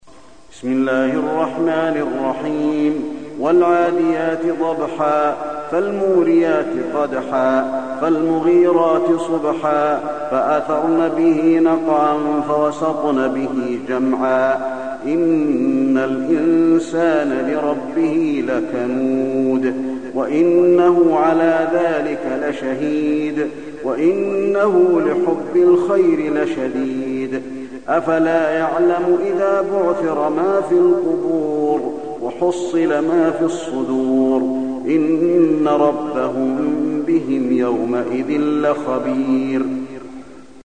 المكان: المسجد النبوي العاديات